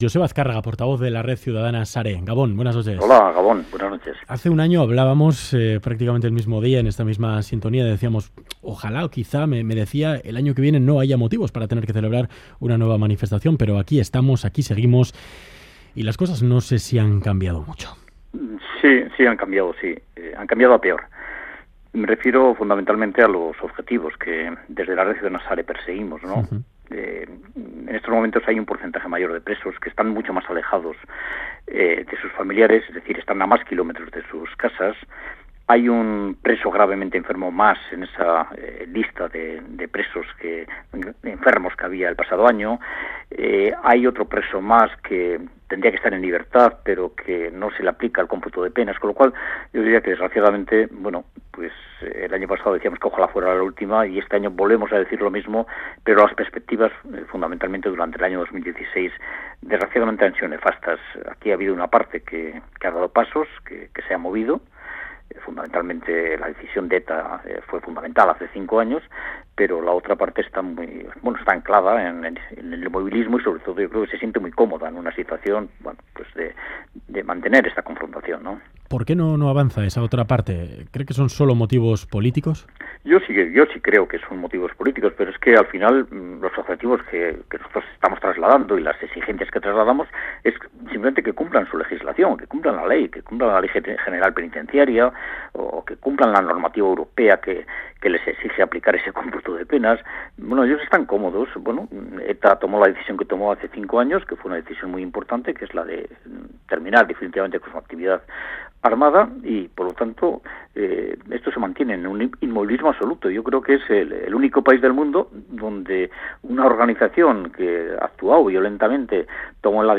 Entrevista al portavoz de SARE Joseba Azkarraga en Ganbara
Entrevista en Ganbara